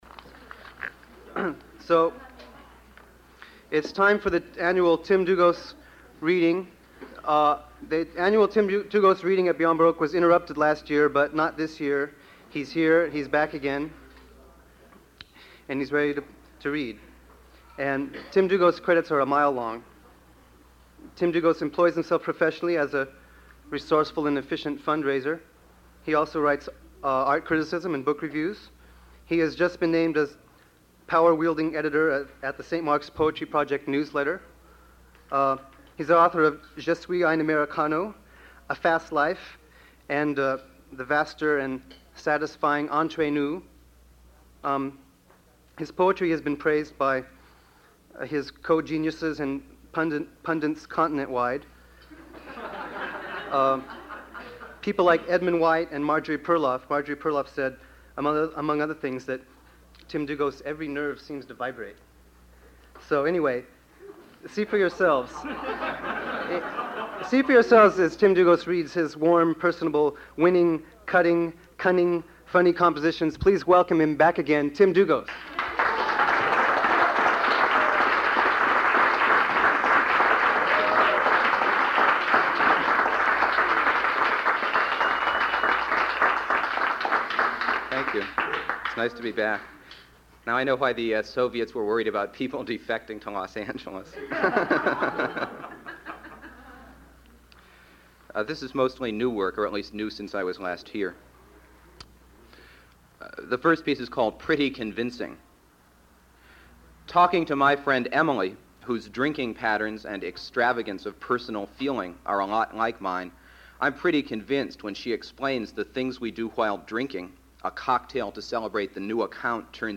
In this recording from May 1984, Dlugos reads his poem "Pretty Convincing," reprinted in A Fast Life (it previously appeared in BOMB and two previous posthumous collections), at Beyond Baroque Literary/Arts Center in Venice, California.